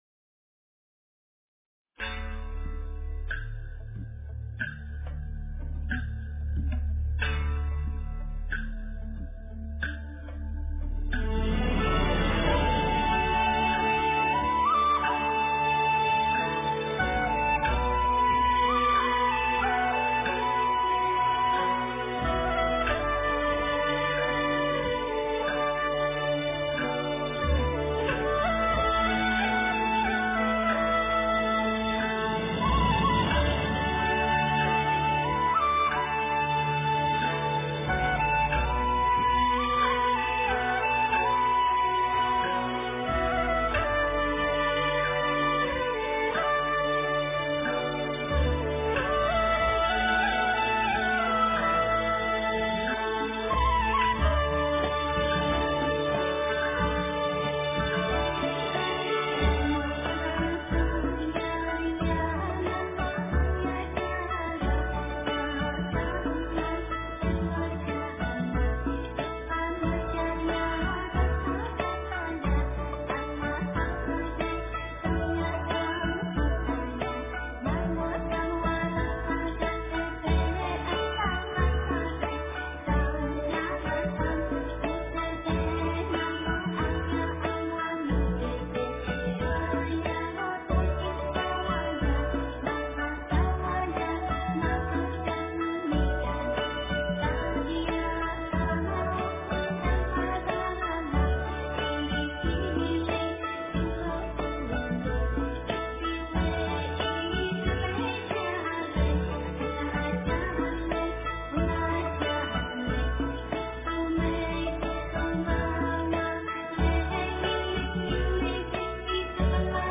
佛音 冥想 佛教音乐 返回列表 上一篇： 诗经-月出(丝弦版